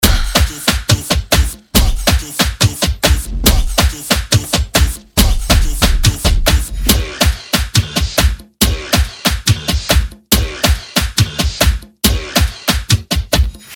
四款高级音色包，共创暗黑风格，专为暗黑街头陷阱音乐和地下节拍打造。
demon_funk_perc_loop_140_bpm.mp3